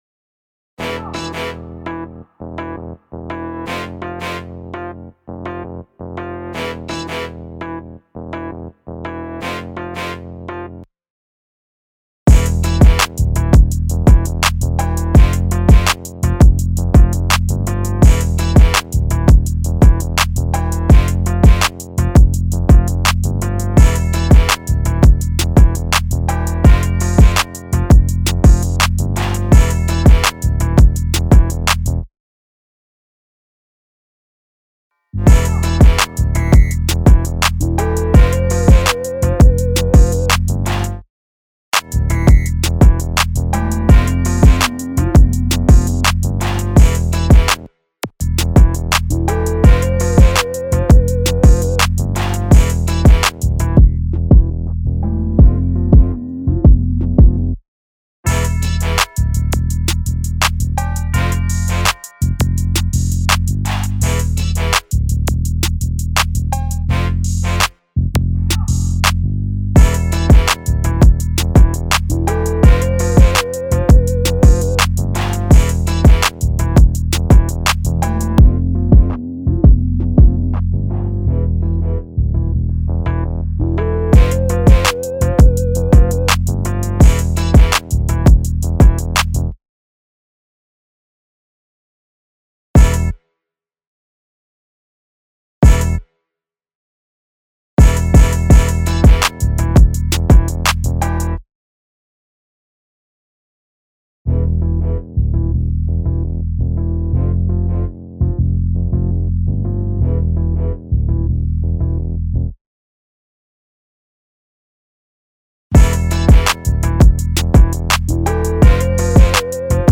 official instrumental
2025 in Hip-Hop Instrumentals